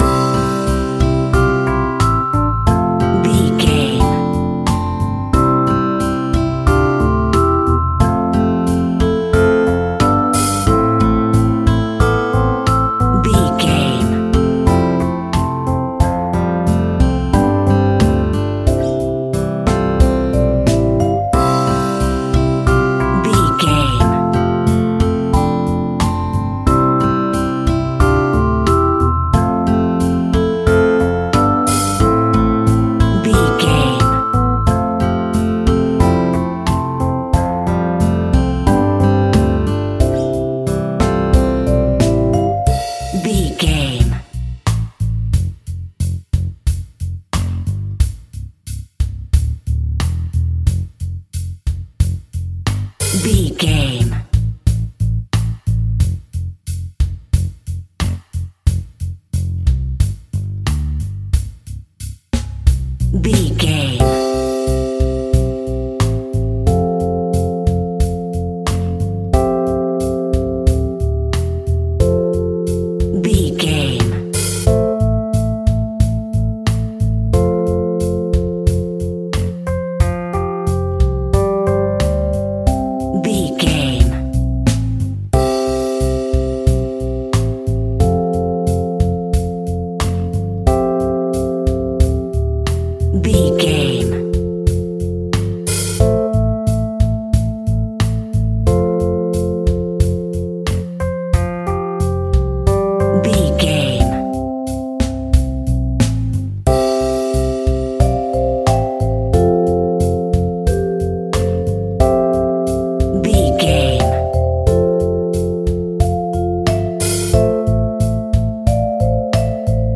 Music for Children.
Uplifting
Ionian/Major
xylophone
acoustic guitar
Keys
drums
bass guitar
piano.